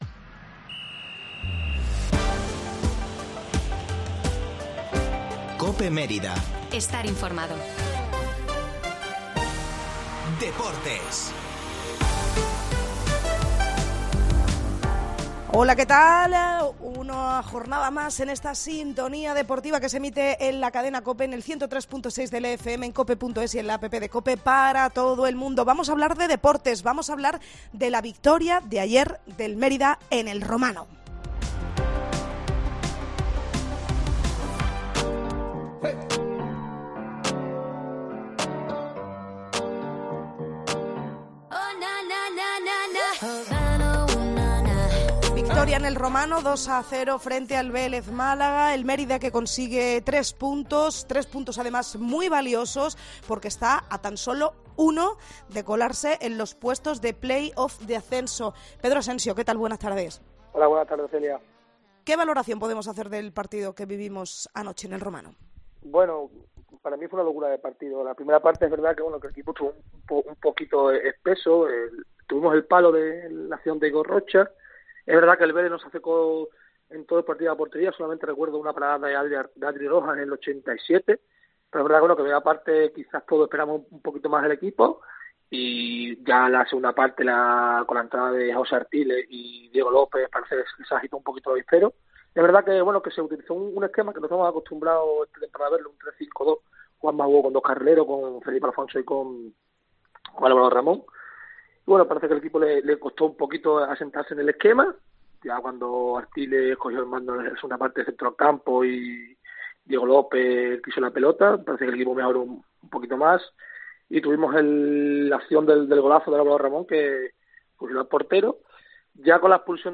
El Mérida en COPE - La Tertulia